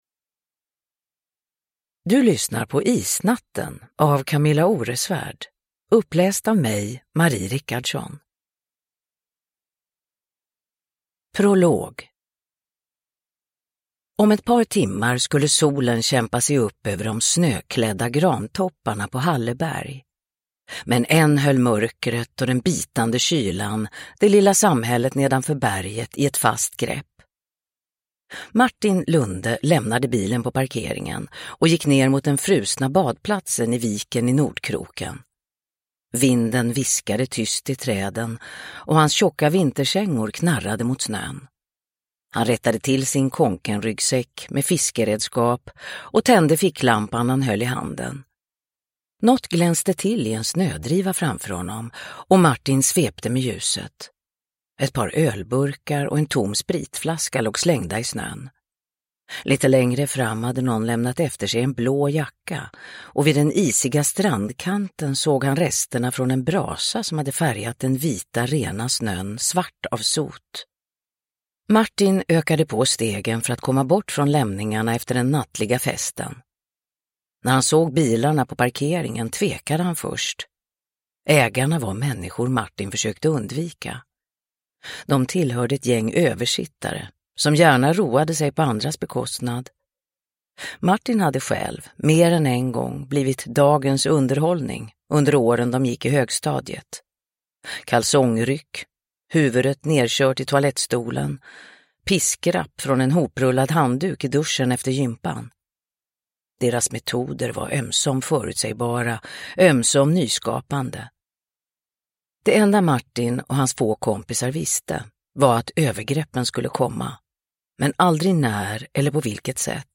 Isnatten (ljudbok) av Kamilla Oresvärd